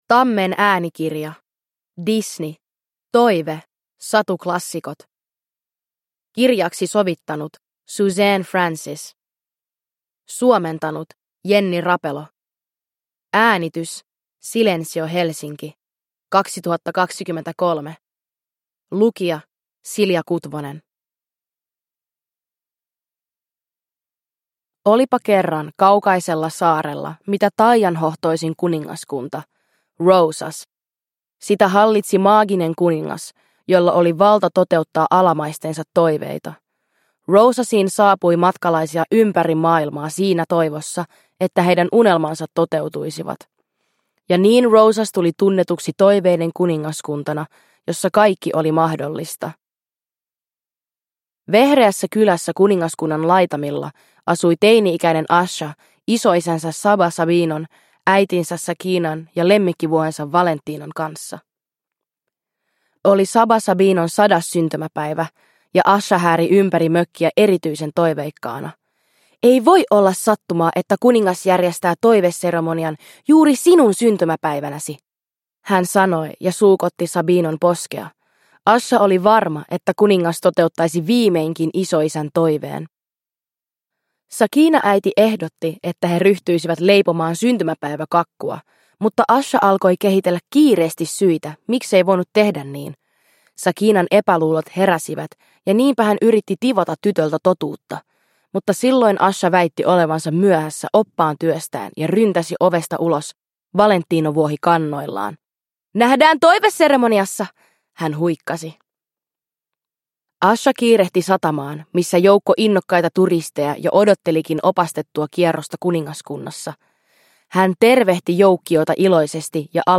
Disney. Toive. Satuklassikot – Ljudbok